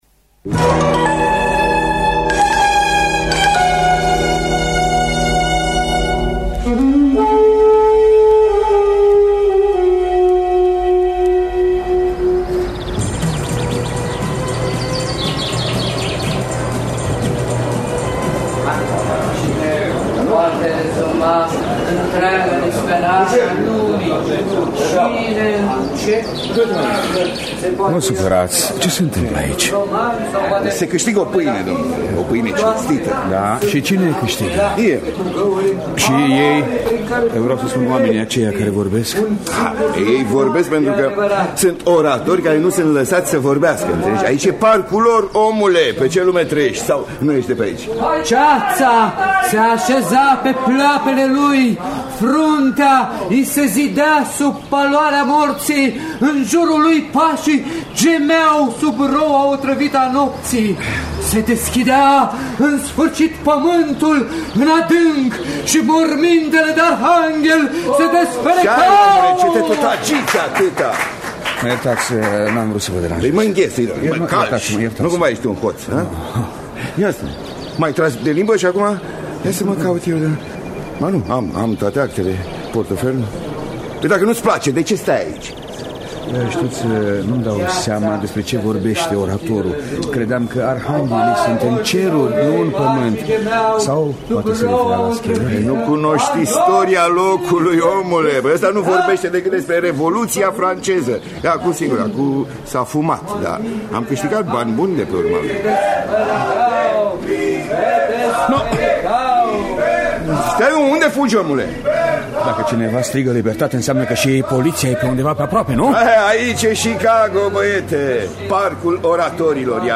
Dramatizarea radiofonică de Elza Cenuşă.